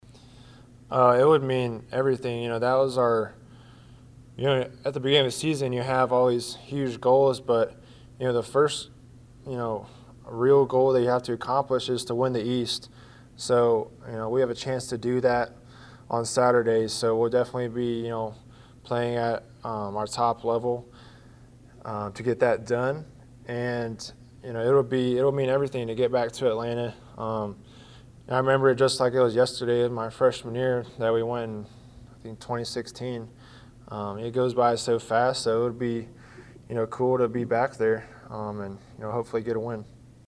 Heisman hopeful Kyle Trask spoke with the media Monday. The senior quarterback discussed what a trip to Atlanta means.